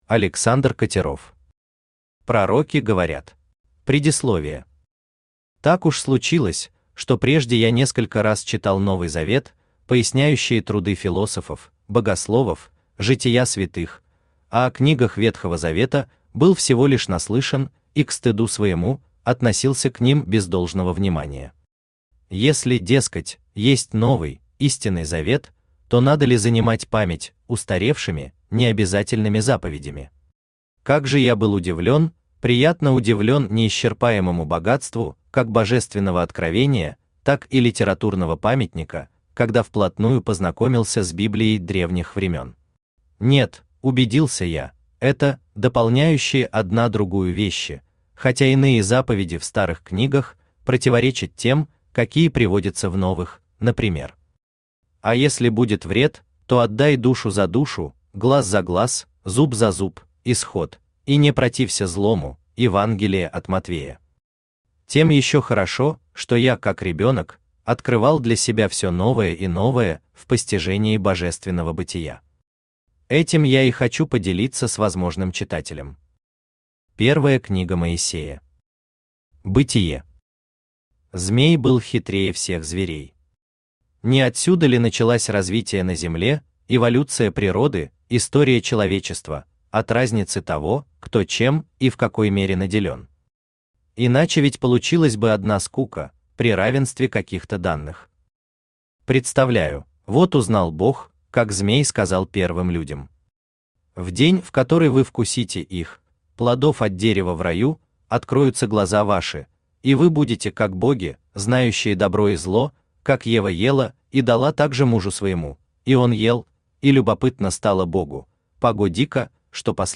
Аудиокнига Пророки говорят | Библиотека аудиокниг
Aудиокнига Пророки говорят Автор Александр Викторович Катеров Читает аудиокнигу Авточтец ЛитРес.